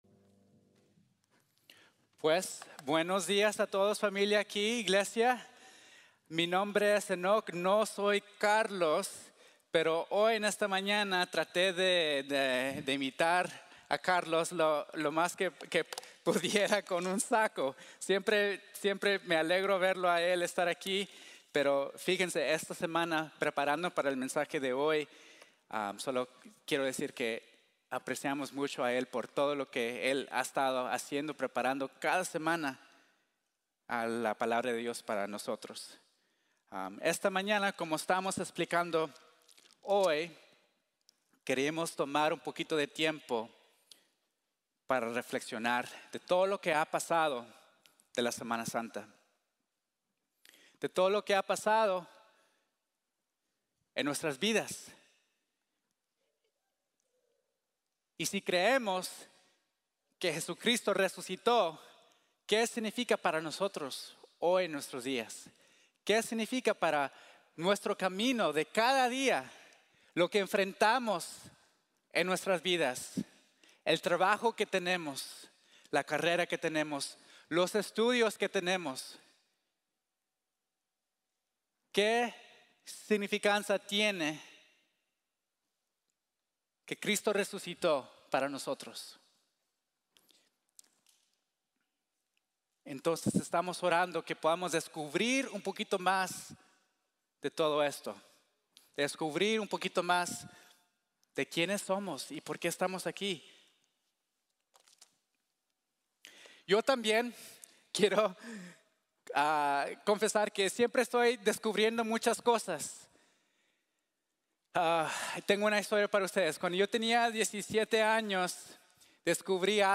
En el camino del descubrimiento | Sermon | Grace Bible Church